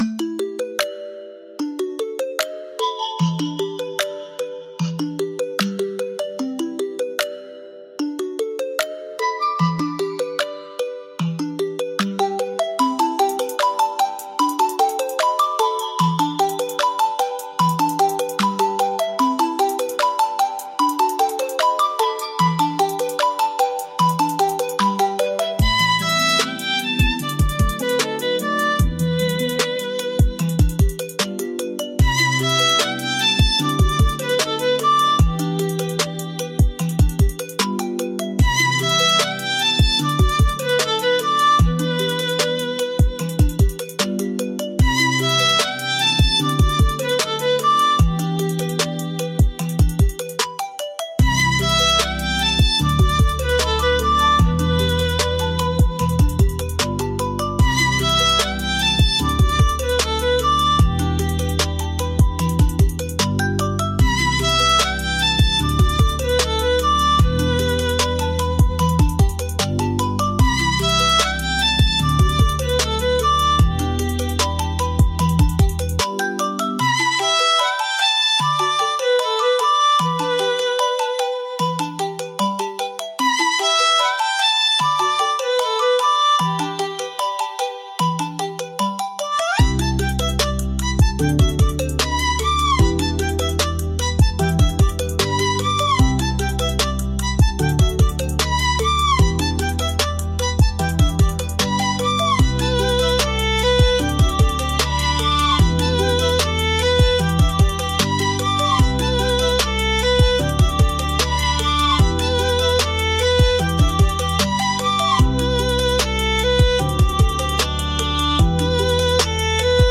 G Major – 75 BPM
Lofi
Trap